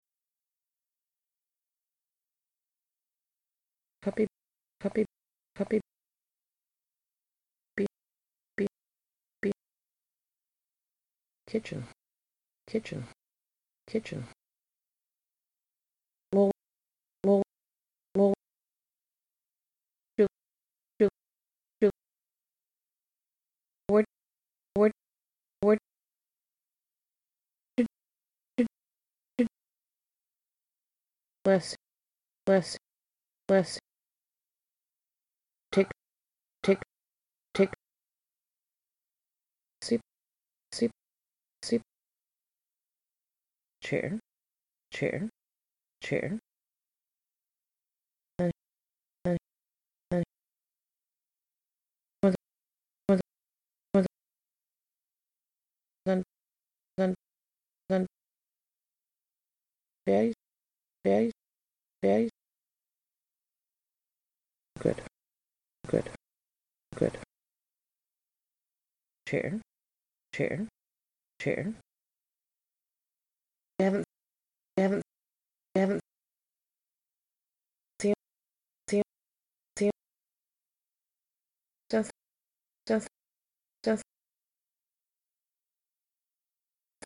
Words extracted from fluent speech
You will hear 20 words that have been extracted from fluent speech.
wordsinfluentspeech.mp3